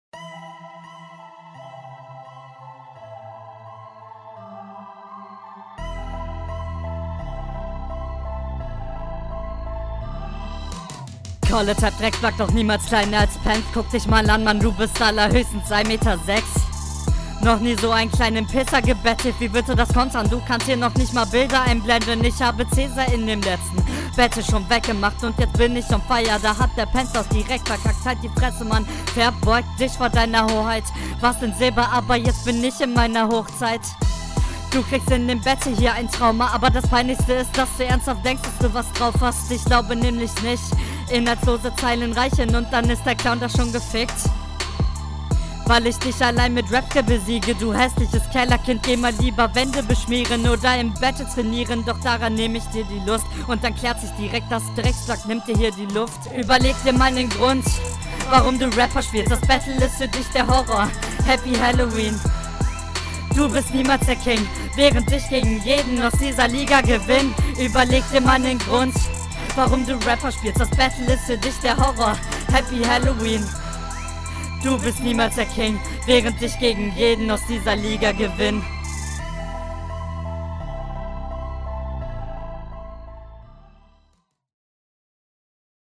Kannst nicht mal gerade am Mic stehen du Knecht, ständig bist du lauter mal leiser!